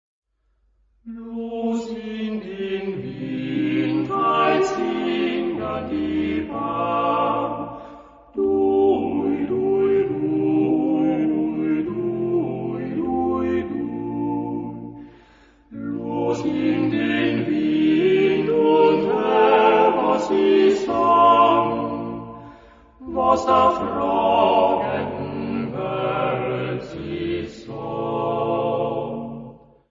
Genre-Style-Form: Secular ; Popular
Mood of the piece: lively
Type of Choir: TTBB  (4 men voices )
Tonality: B flat major